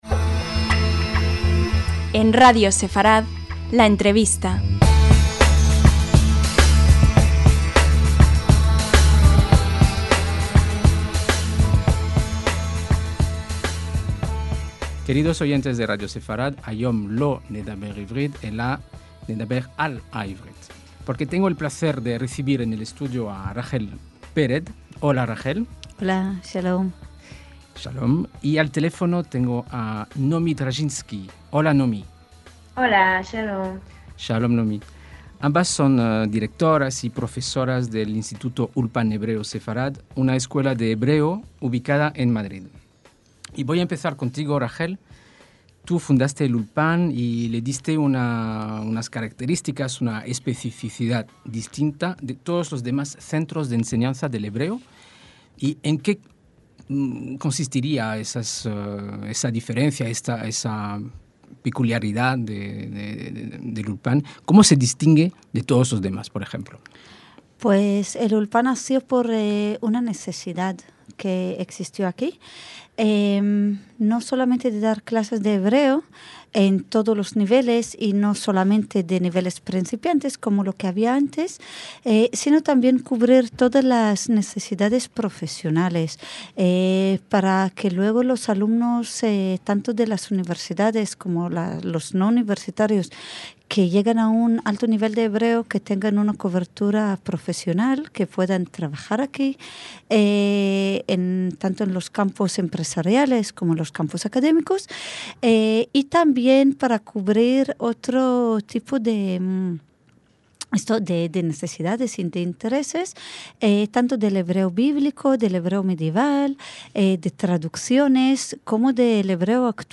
LA ENTREVISTA - En Israel dicen "Ivrit safá kalá" (el hebreo es un idioma fácil), aunque muchos nuevos inmigrantes cambian la frase por "Ivrit safá kashá" (el hebreo es un idioma difícil).